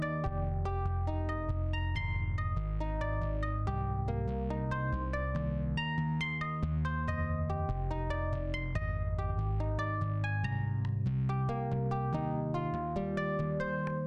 i dont feel like going through all my presets now but here’s six i just did. some are more similar just gritty fm brassy stuff, but i there is a nice quality to the synth where you can get high notes that are clean woody almost digital dx fm sounding but organic while the low notes and chords are fuzzy and warm at the same time. I’ll try to find some of those later, but these are just some i was able to record quickly just now. sorry about the volume differences, i forgot to normalize a couple. also excuse my playing, i suck